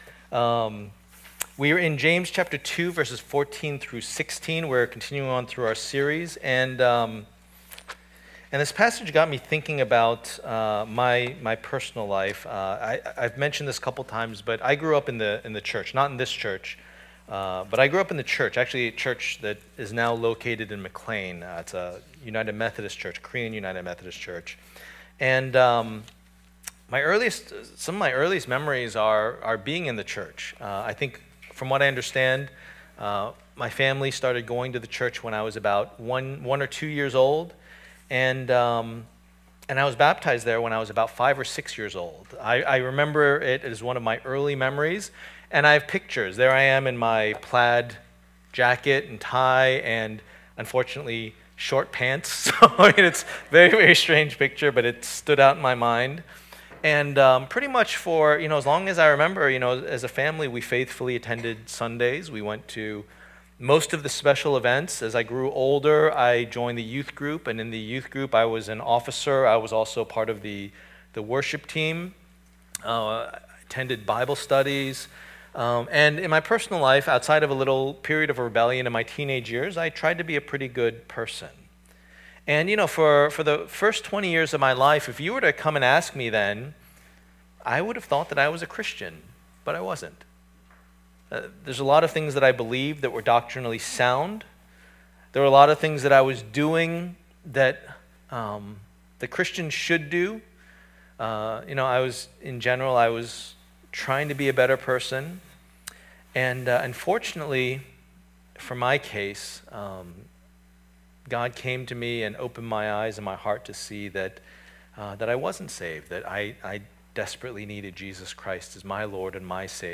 A Study of the Letter of James Passage: James 2:14-26 Service Type: Lord's Day %todo_render% « Favoritism and Justice Who Is Wise?